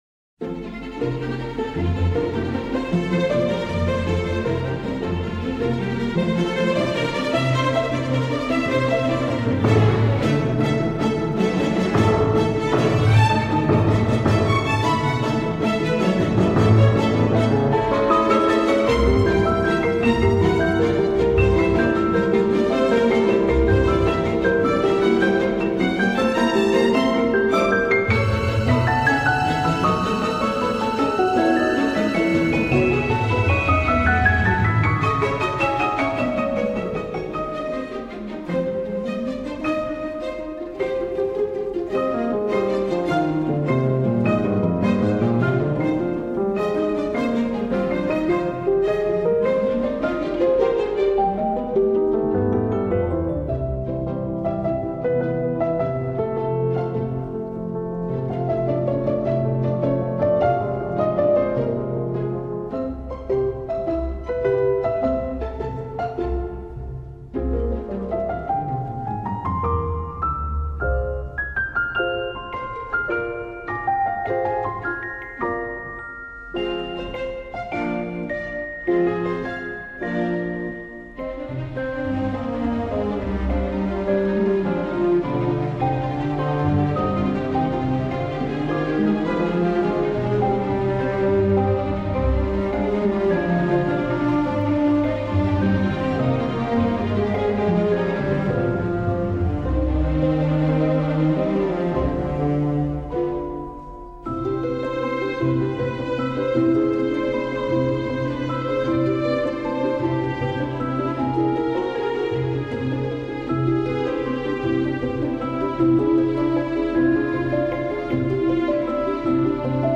Une musique terriblement charmante et addictive.